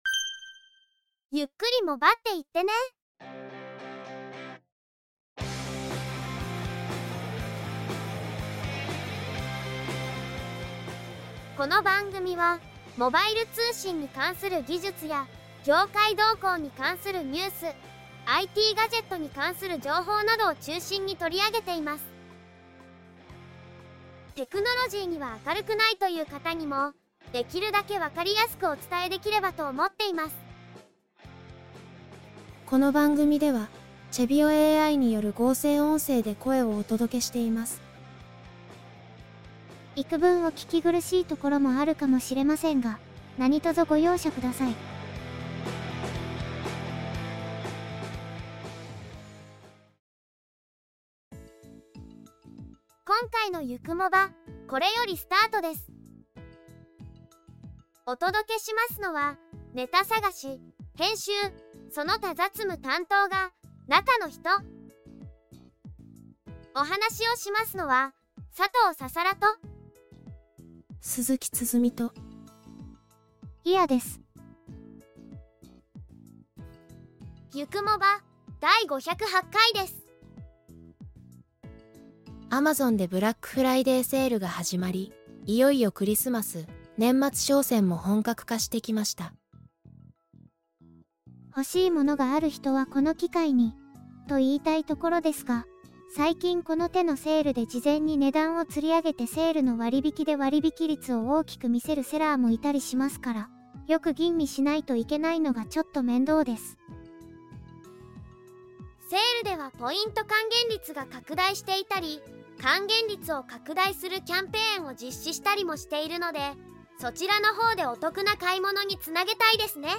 全編合成音声によりお届けいたします。